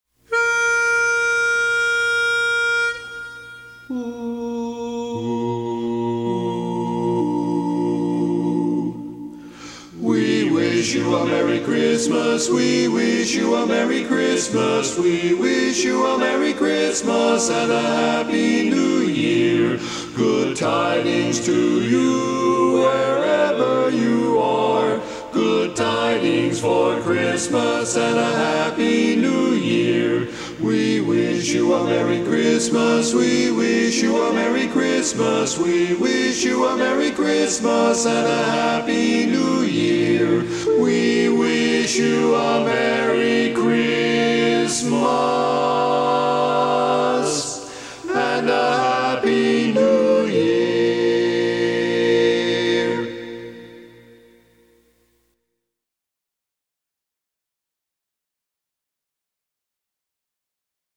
Christmas Songs
Barbershop